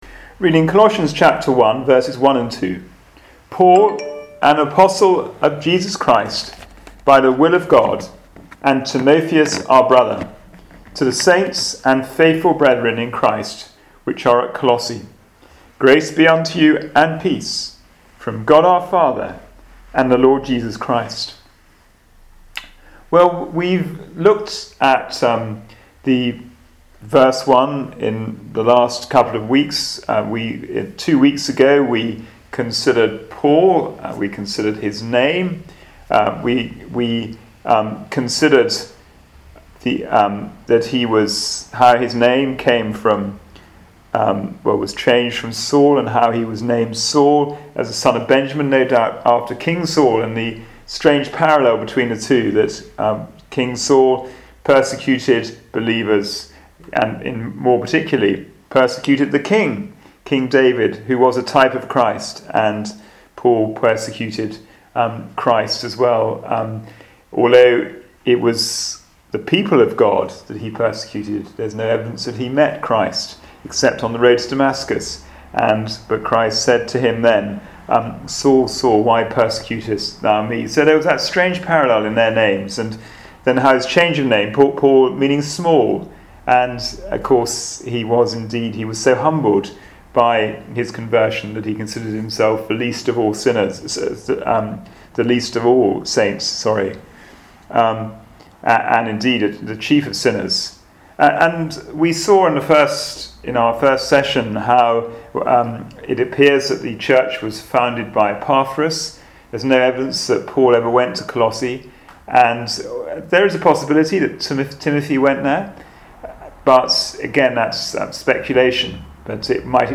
Passage: Colossians 1:1-2 Service Type: Wednesday Bible Study